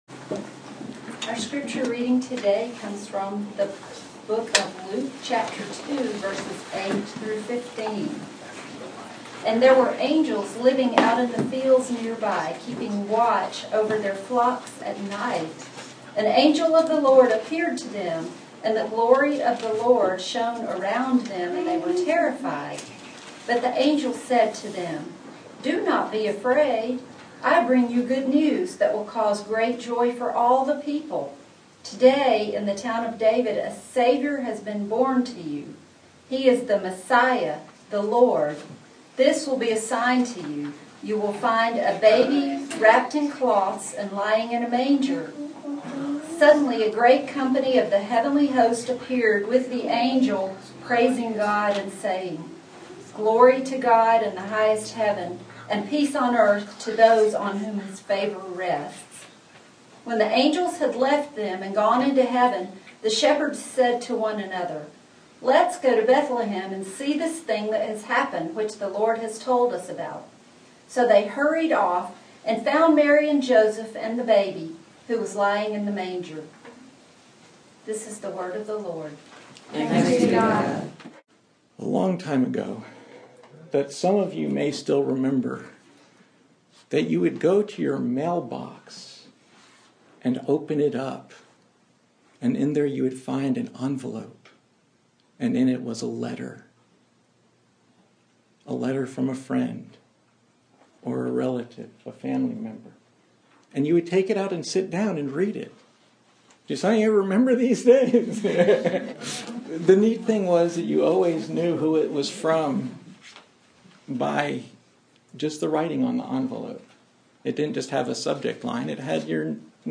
Passage: Luke 2:8-21 Service Type: Sunday Morning